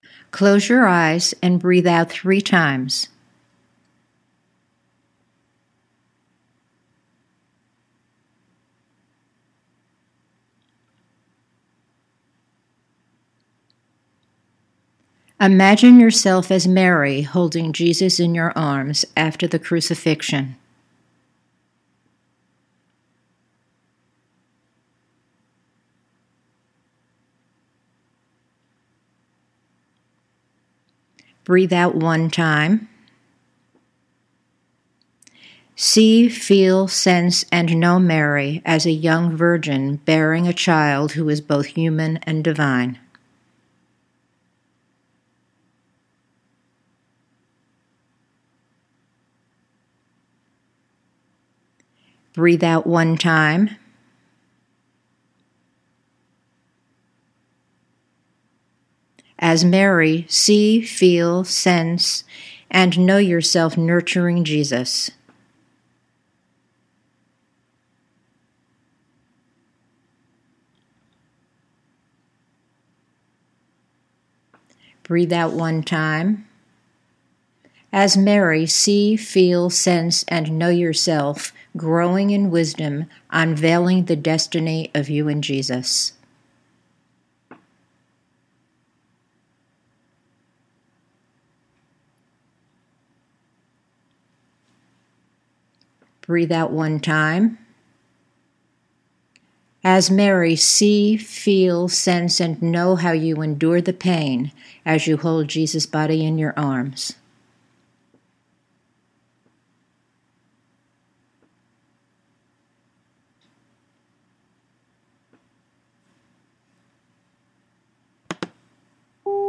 Imagery Narrative: Mary Remembers In Her Heart
NOTE:  There are pauses on the tape between each exercise.